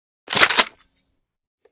shotgun_unjam.ogg